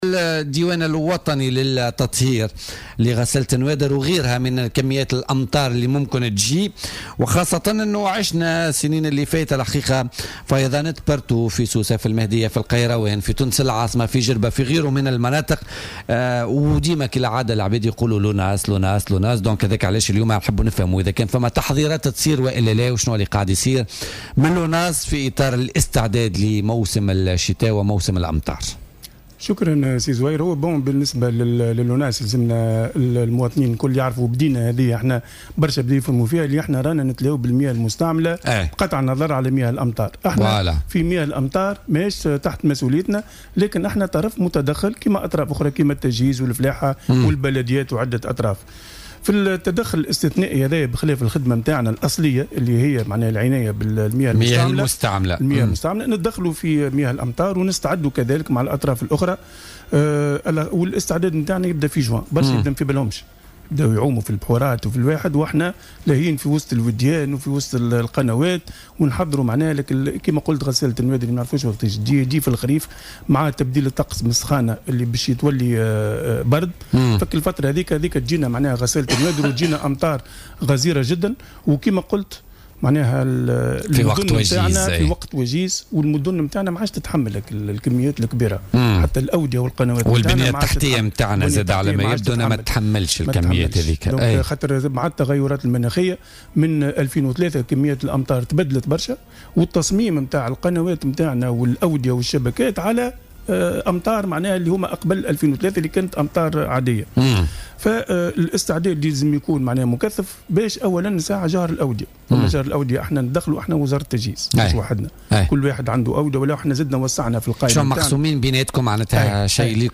وأوضح ضيف "بوليتيكا" على "الجوهرة اف أم" أن تخصصهم الأصلي يتمثل في المياه المستعملة إلا أنه يشمل أيضا مياه الأمطار مع جميع الأطراف استعدادا للتغيرات المناخية بعد الصيف والتي تكون عادة مصحوبة بأمطار غزيرة جدا وفي وقت وجيز تعرف بـ "غسالة النوادر" وأفاد أن الاستعدادات بدأت منذ شهر جوان في واقع الأمر من خلال جهر الأودية أساسا بالاشتراك مع وزارة التجهيز.